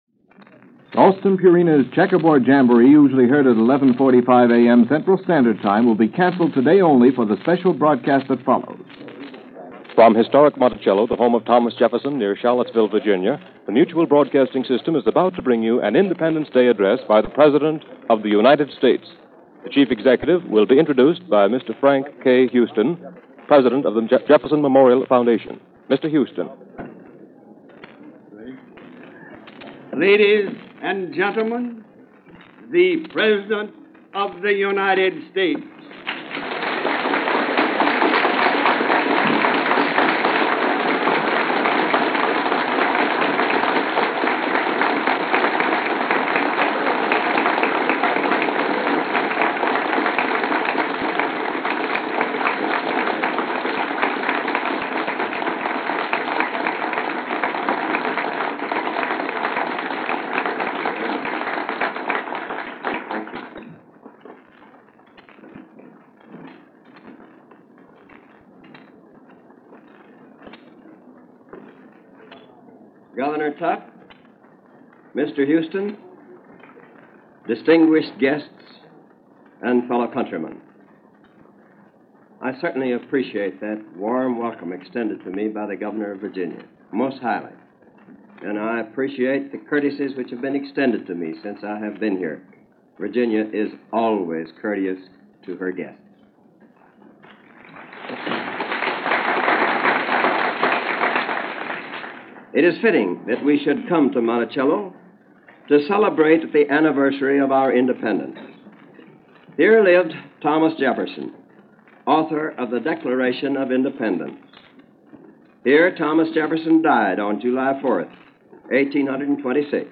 Since we’re coming up on the Fourth of July, and since most everyone will be celebrating in one form or another, I thought I would get a jump on the holiday and run the Fourth of July 1947 Address by President Harry Truman.